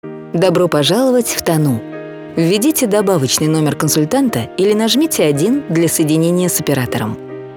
IVR